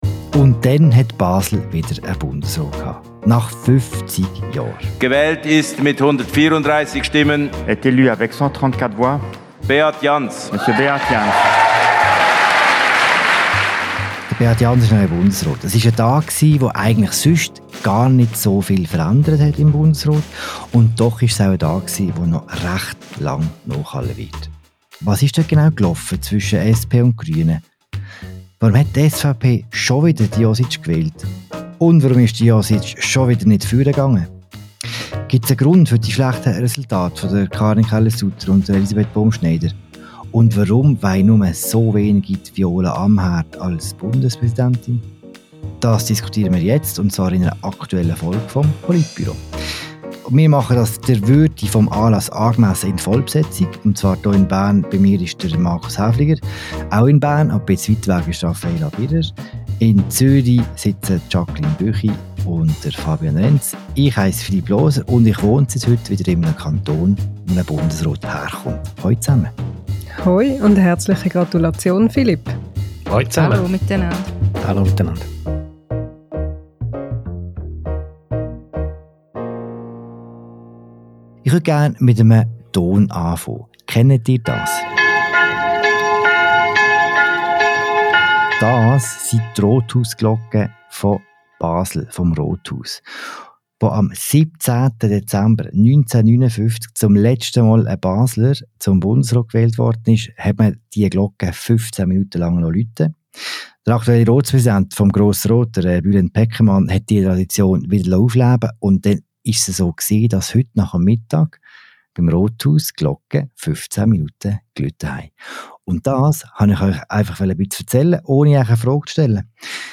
Im Politik-Podcast «Politbüro» diskutiert die Inlandredaktion des «Tages-Anzeigers», wie die Ergebnisse der Bundesratswahl zustande kamen. Was ändert mit dem neuen Bundesrat Beat Jans?